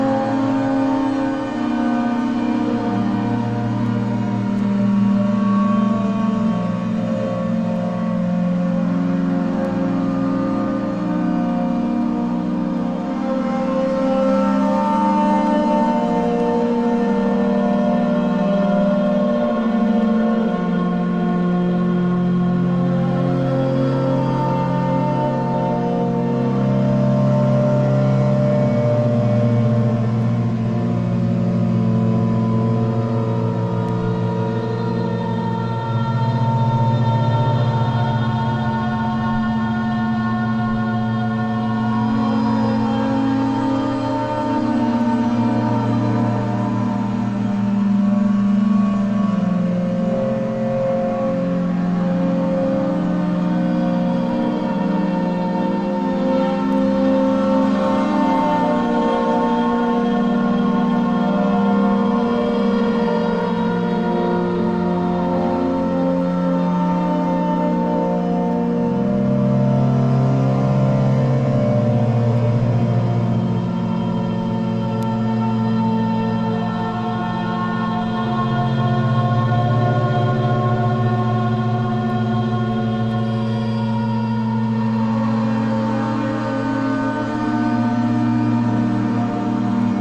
丁寧に織り込まれたアンビエント・レイヤー音楽。
ドローンを用いたドラマティックな展開が◎です。